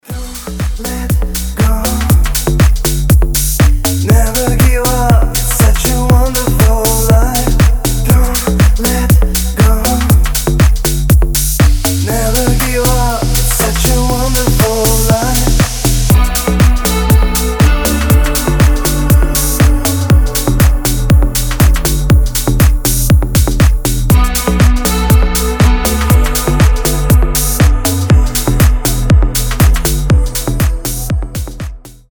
• Качество: 320, Stereo
deep house
чувственные
ремиксы